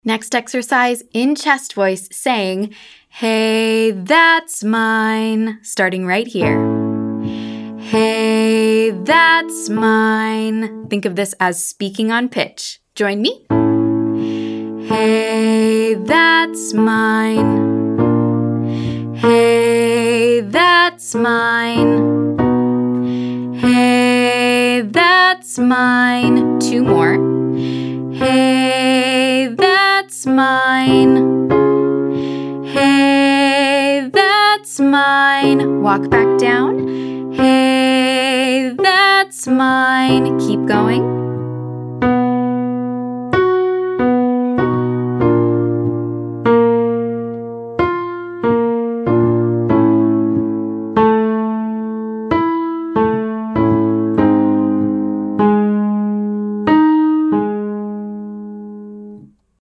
Quick warmup
Exercise 3: Chest voice Hey, that’s mine 1-51